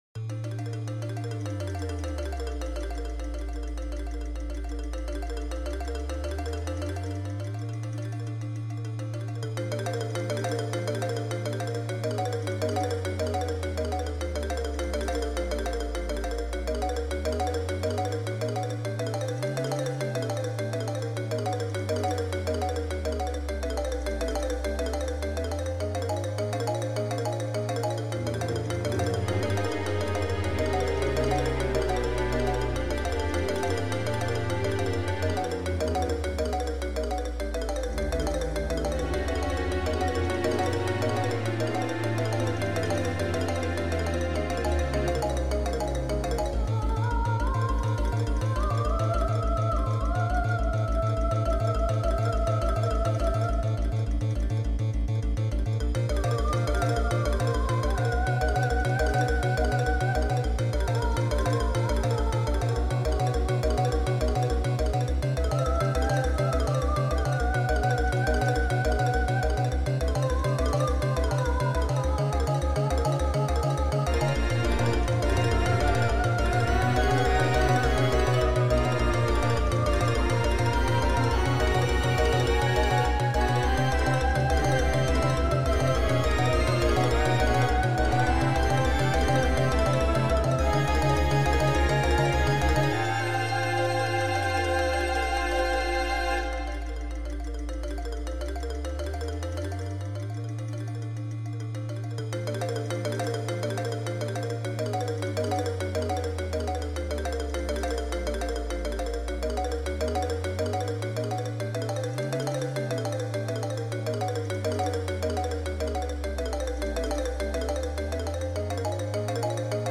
spooky pony remix
genre:remix